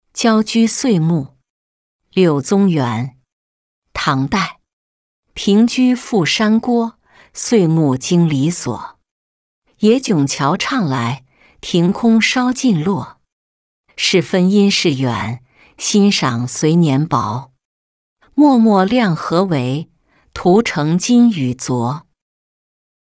郊居岁暮-音频朗读